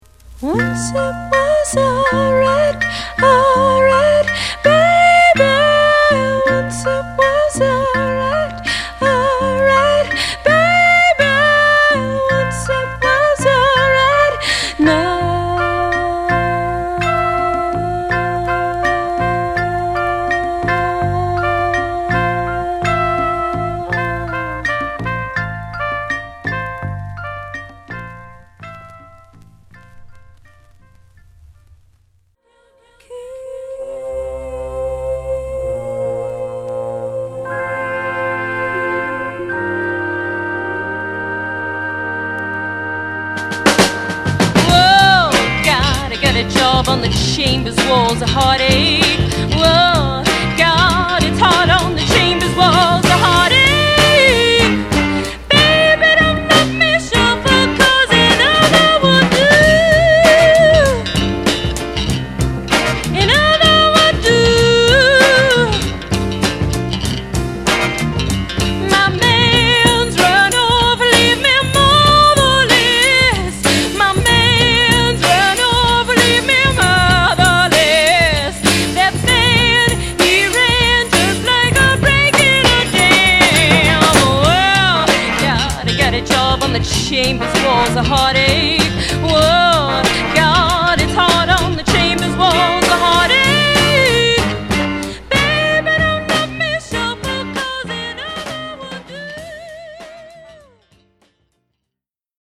曲終わり部分です